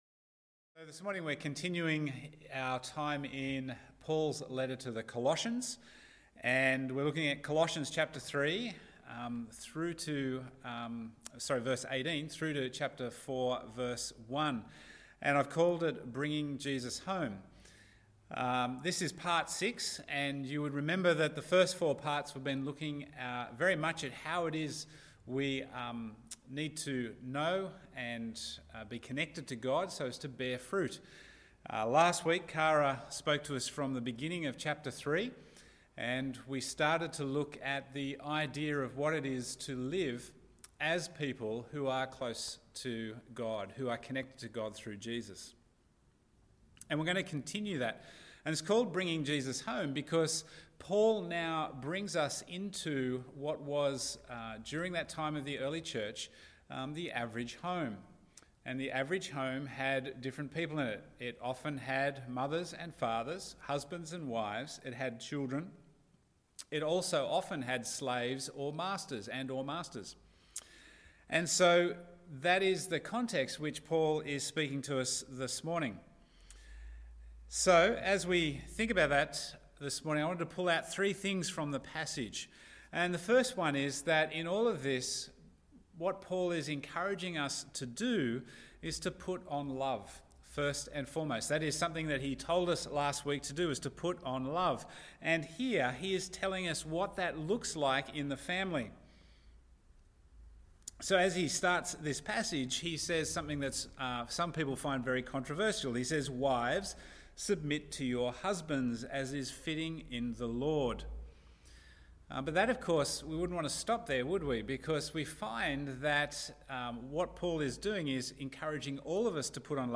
Bible Text: Colossians 3:18 – 4:1 | Preacher